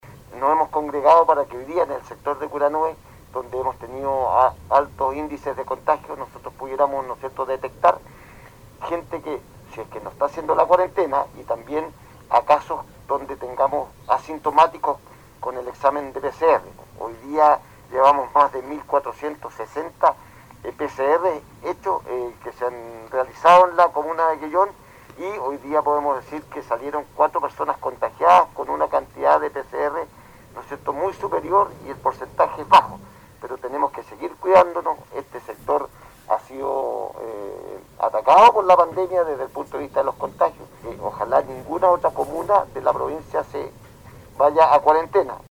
El operativo en Curanué, llevado a efecto el día sábado, contó con la presencia del gobernador provincial Fernando Bórquez, quien manifestó que esta acción del personal de salud de Quellón, tiene que ver con el alza de casos en esa zona, señalando que “OJALÁ NINGUNA OTRA COMUNA DE CHILOÉ INGRESE A LA FASE DE CUARENTENA”